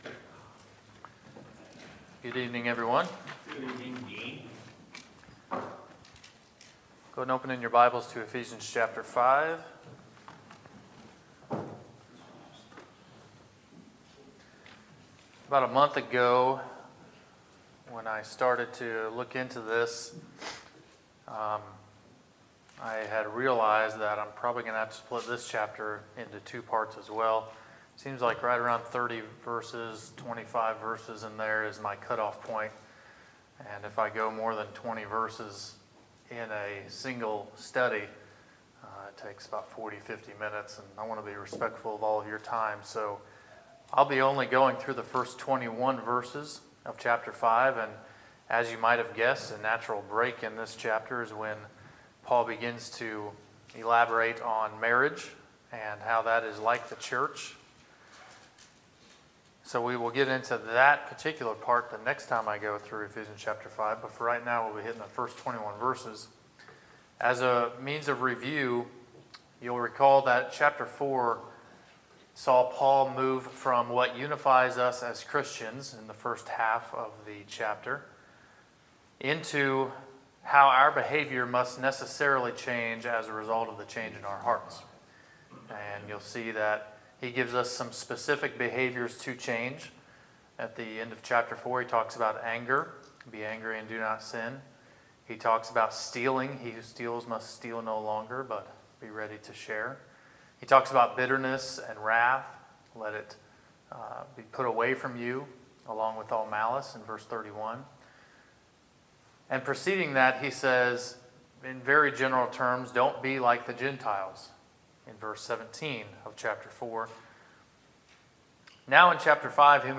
Sermons, March 4, 2018